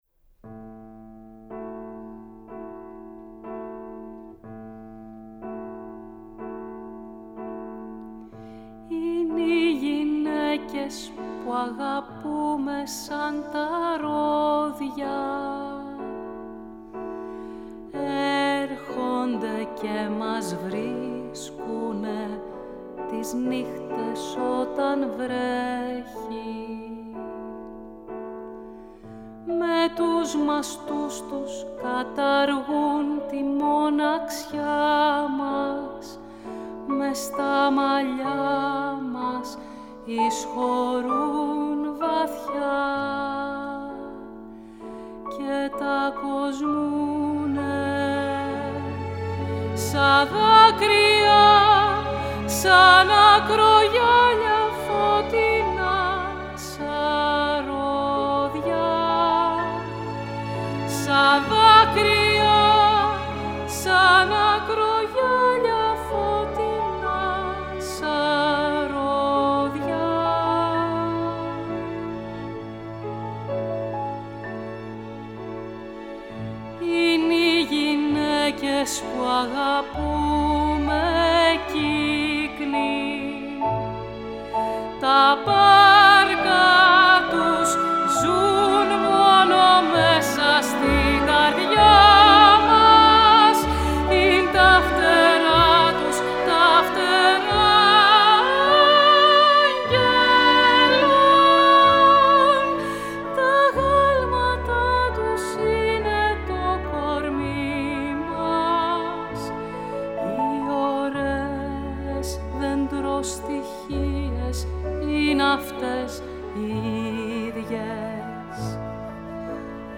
Ηχογράφηση στο STUDIO B, Παρασκευή 16 Οκτωβρίου 2020
Στο πιάνο ο συνθέτης